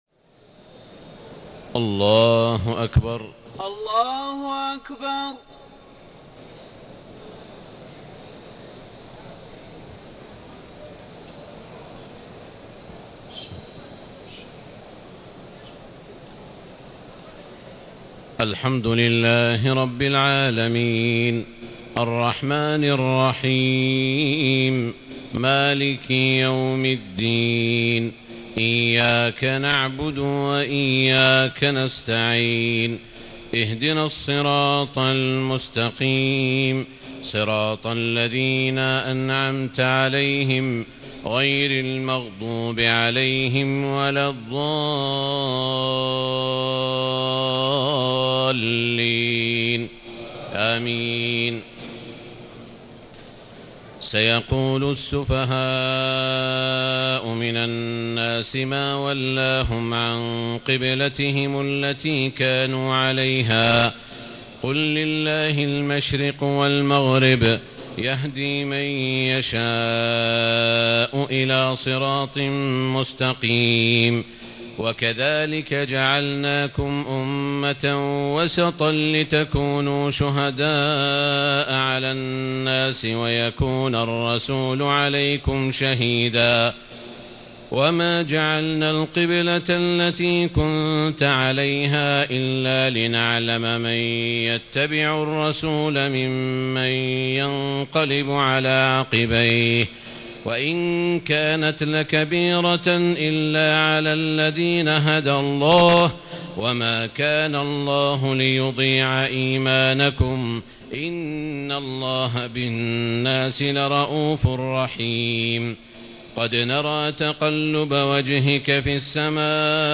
تهجد ليلة 22 رمضان 1439هـ من سورة البقرة (142-218) Tahajjud 22 st night Ramadan 1439H from Surah Al-Baqara > تراويح الحرم المكي عام 1439 🕋 > التراويح - تلاوات الحرمين